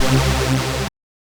Synth Stab 20 (C).wav